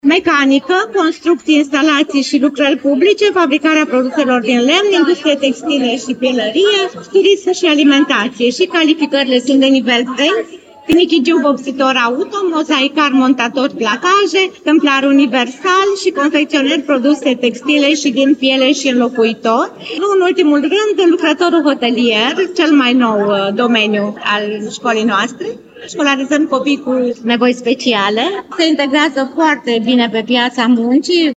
Centrul Judeţean de Resurse şi Asistenţă Educaţională Suceava a organizat astăzi, la Shopping City, Târgul Ofertelor Educaționale.